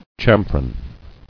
[cham·fron]